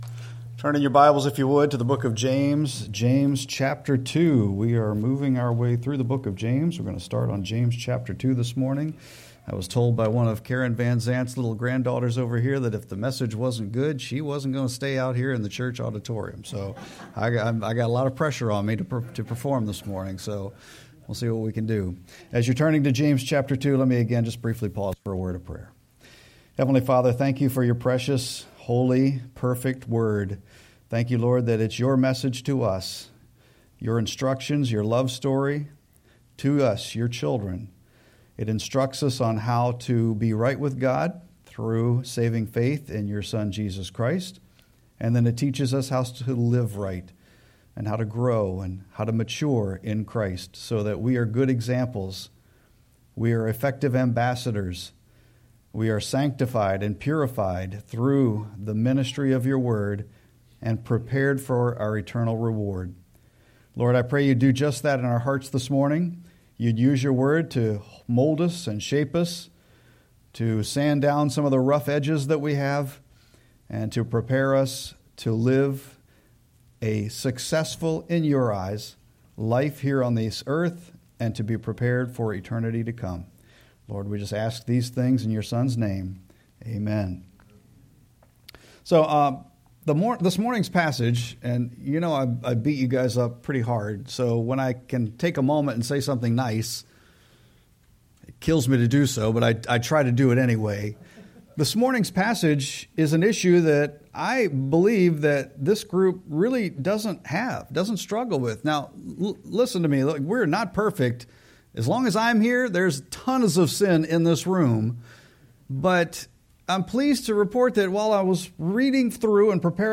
Sermon-6-22-25.mp3